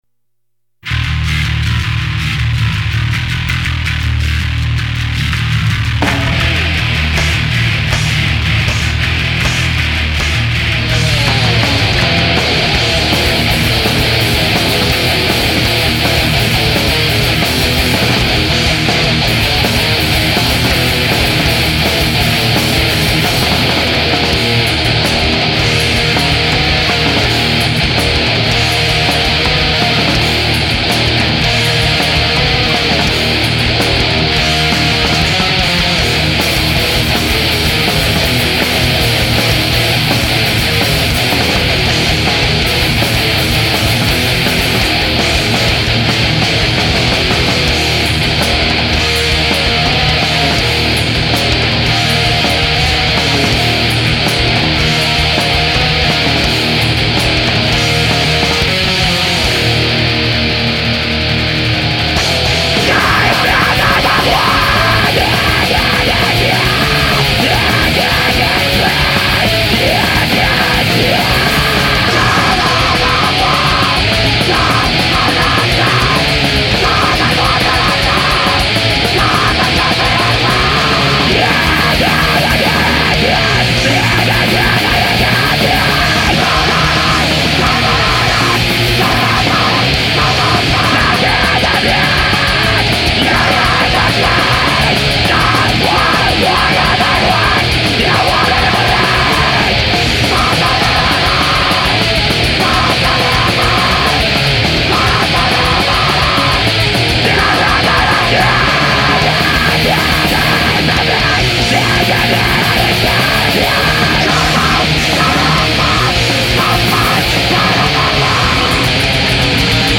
Your sound has been compared to early death